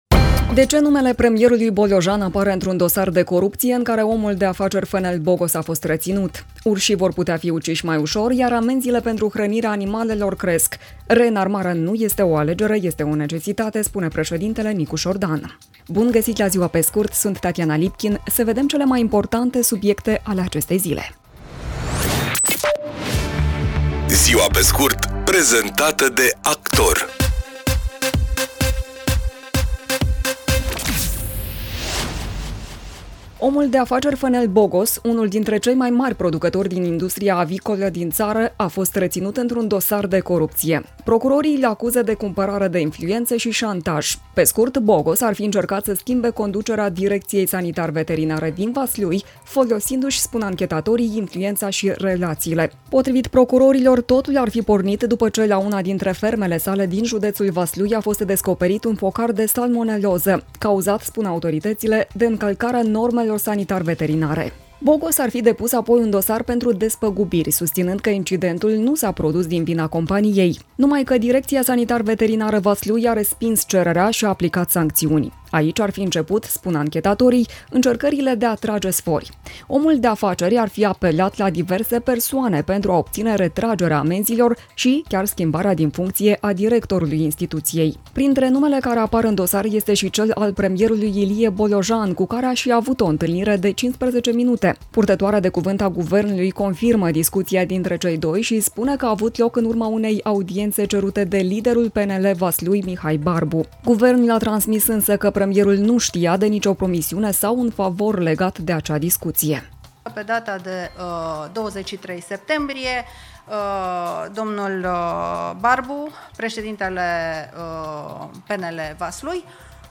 „Ziua pe scurt” este un podcast zilnic de actualitate care oferă, în doar zece minute, o sinteză a principalelor cinci știri ale zilei. Formatul propune o abordare prietenoasă, echilibrată și relaxată a informației, adaptată publicului modern, aflat mereu în mișcare, dar care își dorește să rămână conectat la cele mai importante evenimente.